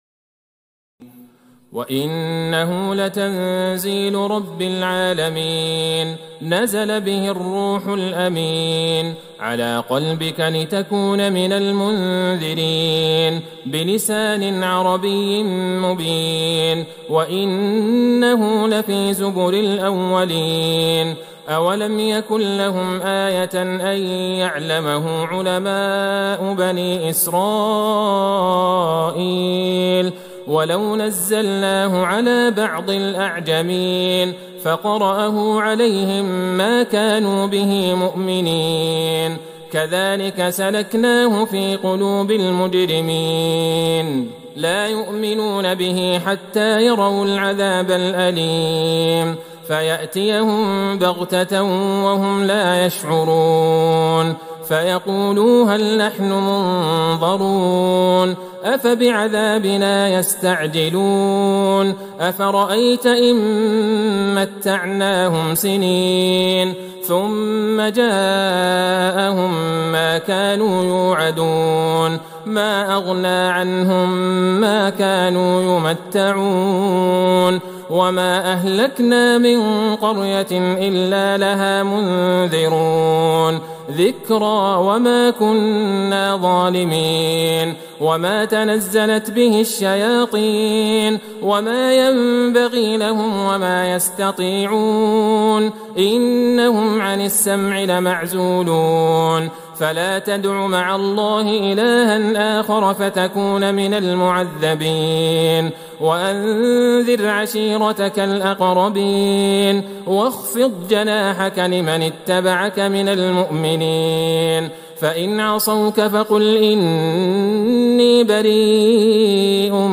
تراويح ٢٣ رمضان ١٤٤١هـ من سورة الشعراء { ١٩٢-٢٢٧ } والنمل { ١-٥٨ } > تراويح الحرم النبوي عام 1441 🕌 > التراويح - تلاوات الحرمين